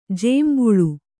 ♪ jēmbuḷu